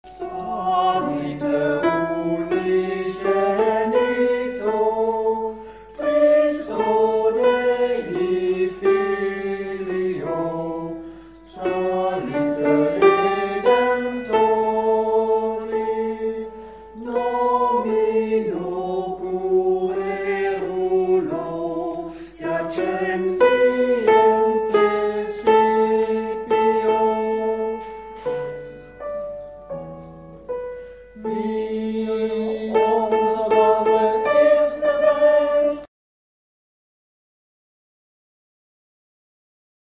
ingezongen in huiselijke kring